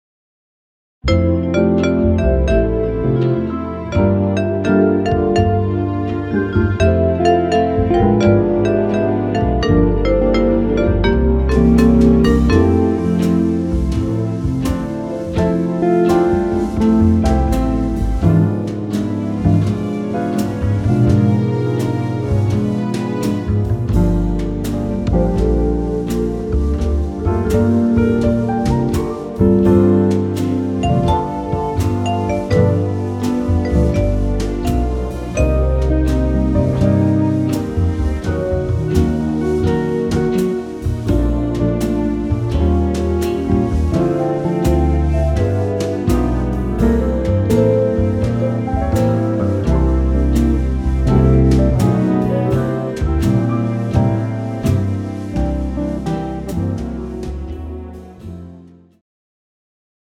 jazz ballad style
tempo 84 bpm
male backing track
This track is in slow tempo jazz ballad style.